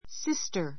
sister 小 A1 sístə r スィ ス タ 名詞 複 sisters sístə r z スィ ス タ ズ ❶ 姉 , 妹 参考 ふつうは姉妹 しまい の区別をせずに単に sister という.